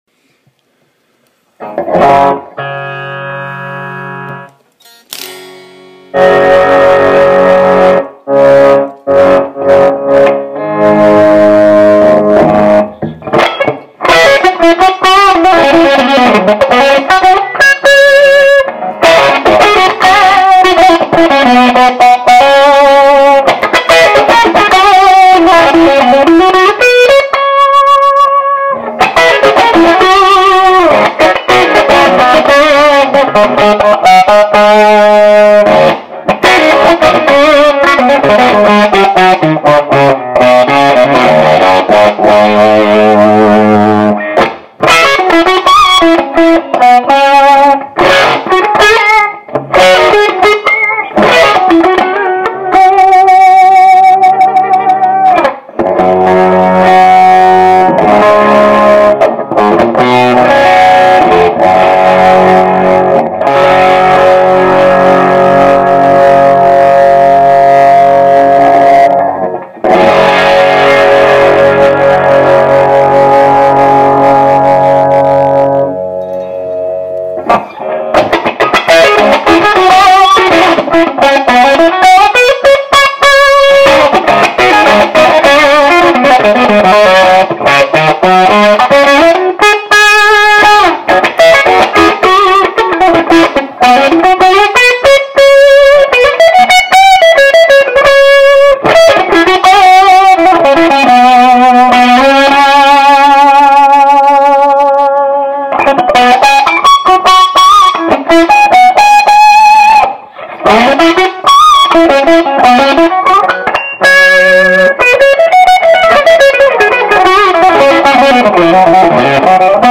Here are a couple of down and dirty sound clips. Recorded on iPhone with open air speaker.
This is without NFB and with a GZ34 tube.